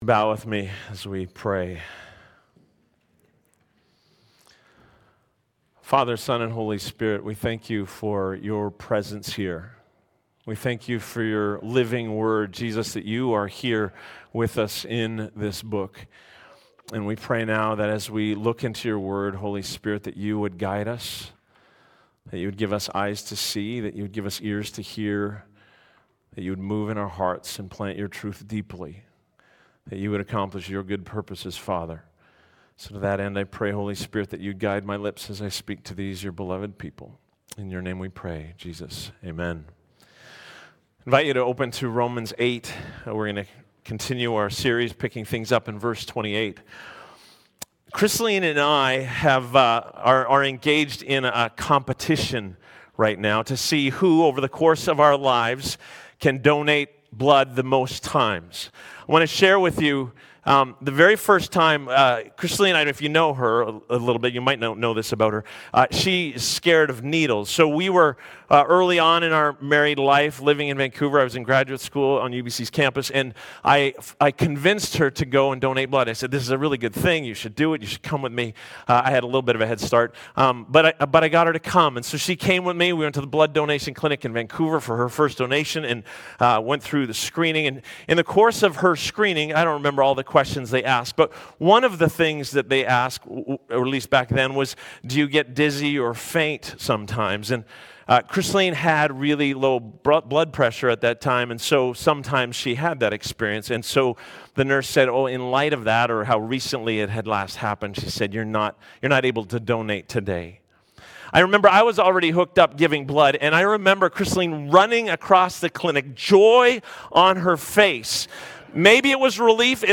Sermons | Sunrise Community Church